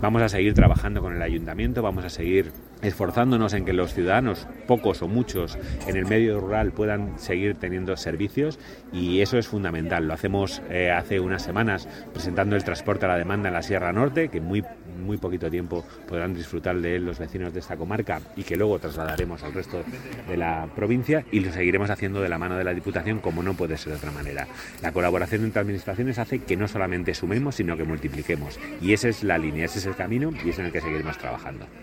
Eusebio Robles, delegado de la Junta en Guadalajara, durante la inaguración de la Casa Consistorial de Villaseca de Henares